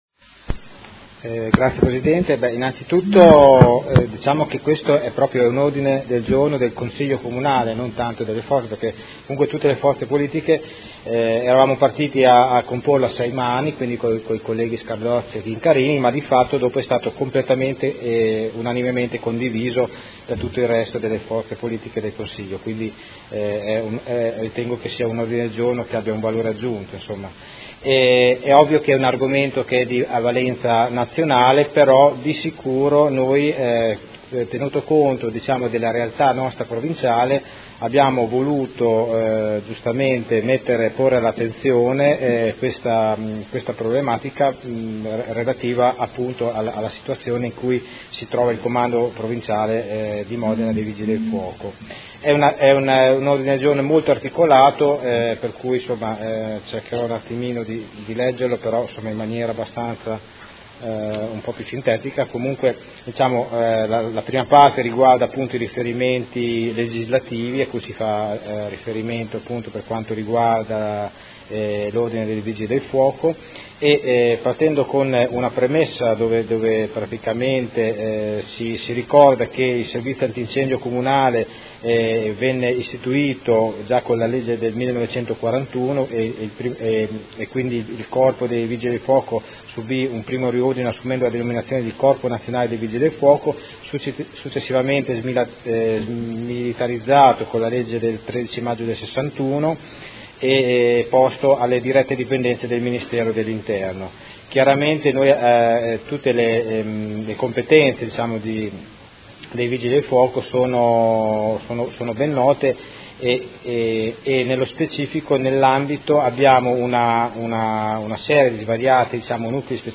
Vincenzo Stella — Sito Audio Consiglio Comunale
Seduta del 31/03/2016. Ordine del Giorno presentato dai Consiglieri Stella (P.D.), Scardozzi (M5S), Chincarini (Per Me Modena), Cugusi (SEL), Santoro (Area Popolare), Montanini (CambiAMOdena), Rocco (FaS – Sinistra Italiana), Galli (F.I.) avente per oggetto: A sostegno dei Vigili del Fuoco del Comando Provinciale di Modena